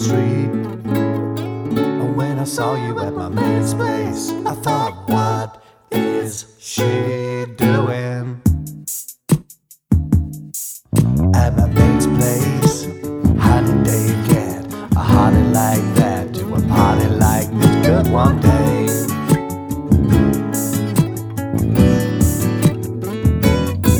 No Backing Vocals Comedy/Novelty 4:00 Buy £1.50